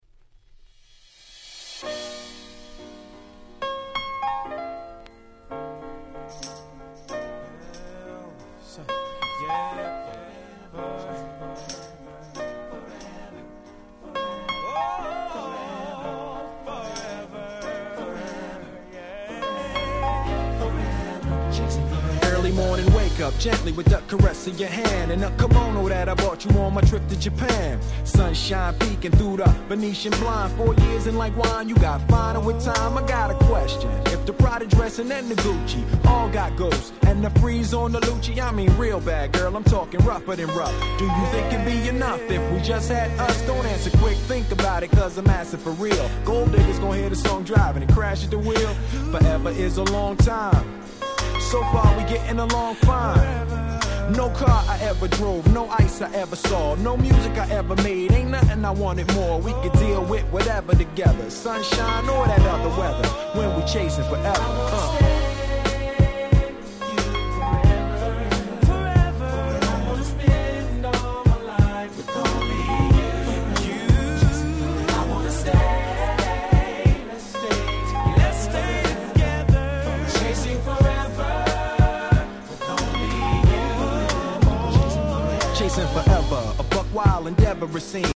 97' Super Hit Hip Hop LP !!